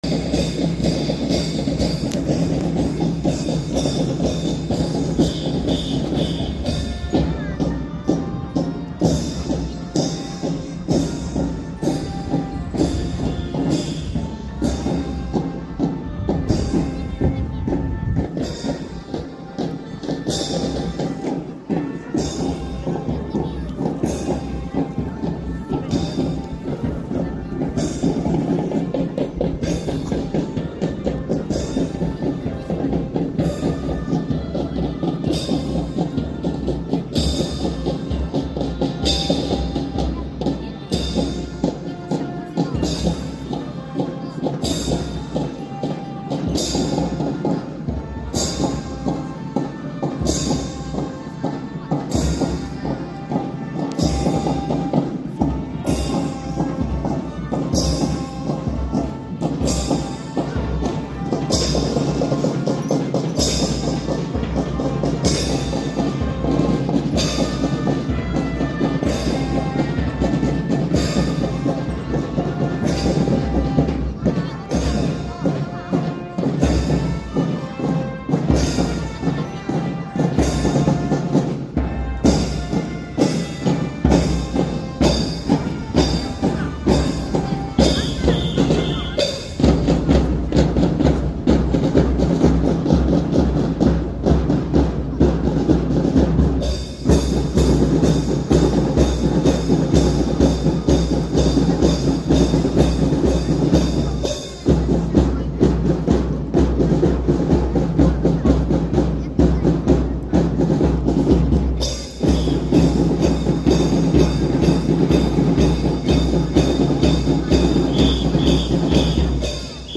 1021 第59代 鼓笛隊